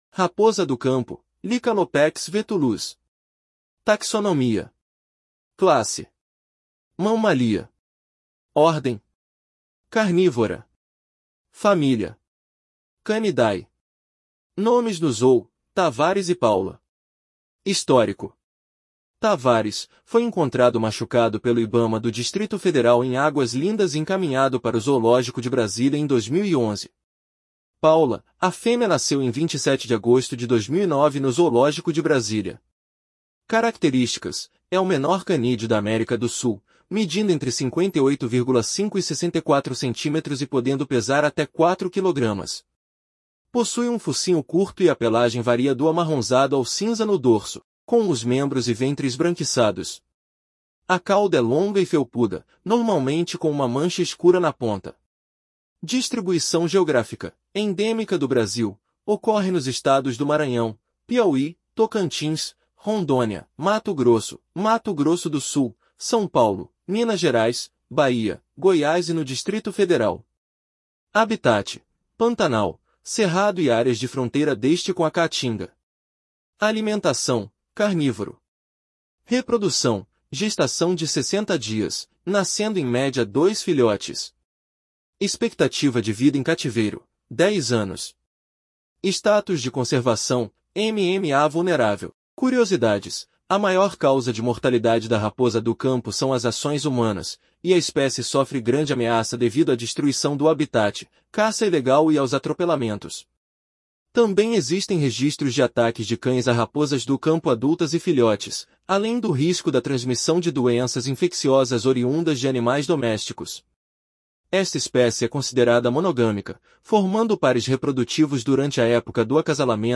Raposa-do-campo